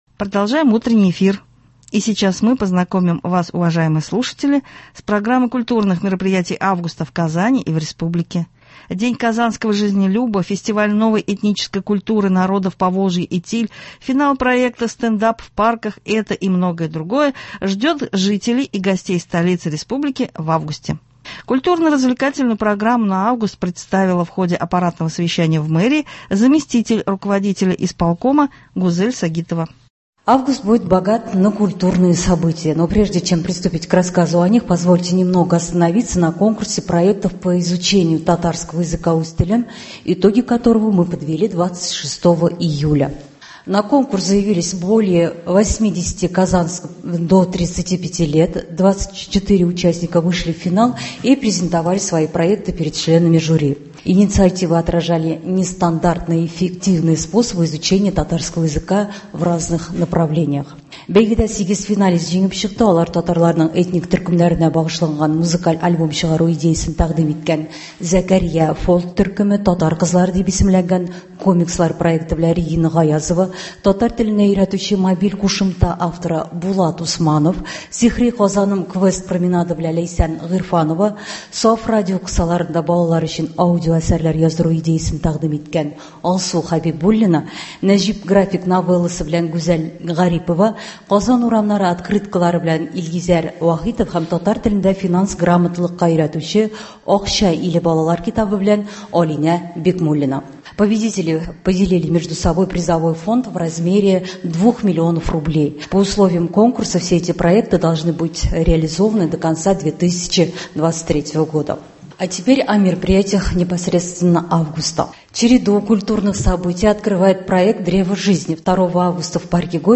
Сейчас прозвучит беседа с депутатом Госдумы от Татарстана Татьяной Ларионовой, она подведет итоги весенней сессии , затем расскажет о о том, как Татарстан готовится к новому учебному году.